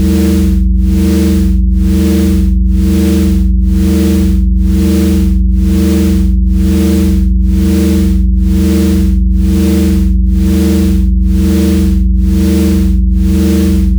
낮은 A음, 55Hz입니다.
14초 루프를 생성하는 스크립트를 작성했습니다. FM 합성을 사용하여 금속성 ‘쨍그랑’ 소리(정수 비율이 아닌 것이 중요합니다. 완벽한 배음은 너무 깨끗하고 디지털적으로 들립니다)를 만들고 필터링된 노이즈를 추가하여 축축한 지하실에서 발견되는 릴 투 릴 녹음의 테이프 히스를 모방했습니다.
“바람” 변조는 피치를 약간만 드리프트시켜 불안정하게 느끼게 하는 느린 LFO입니다.
# 비율 1:2.41은 속이 빈 산업적인 울림을 만듭니다.
묵직하고 산업적인 드론에는 뭔가 위안이 됩니다.